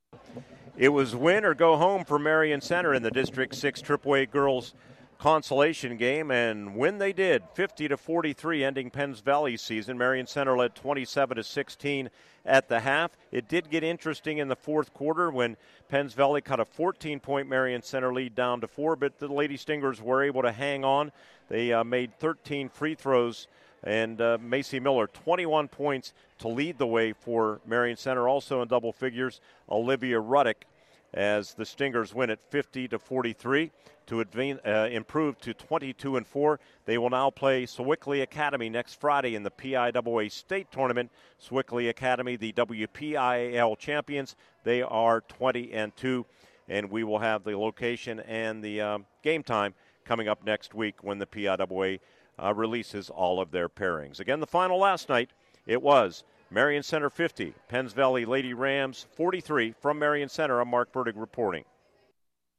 recap-penns-valley-girls-at-marion-center.mp3